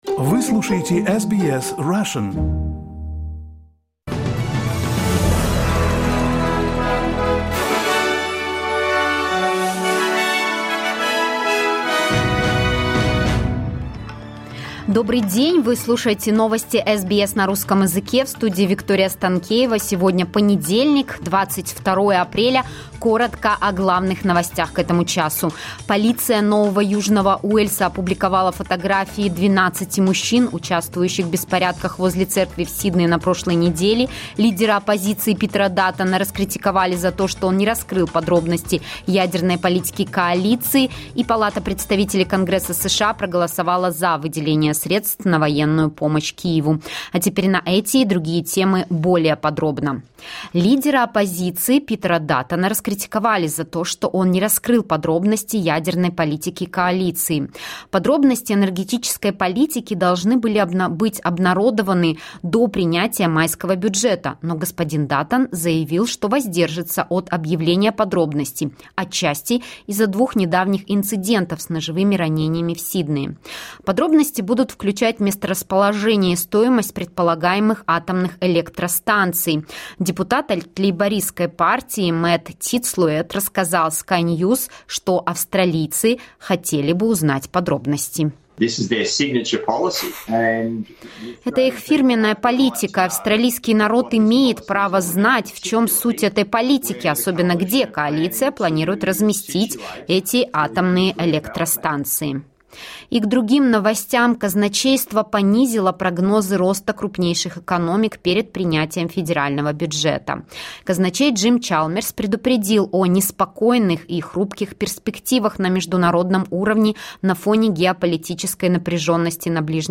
Latest news headlines in Australia from SBS Russian